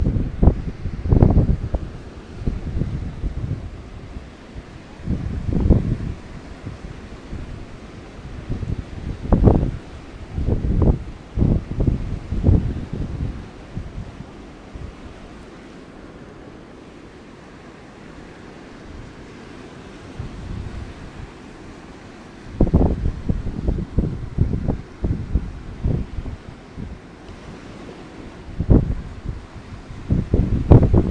Listening to the breeze and waves in Dahab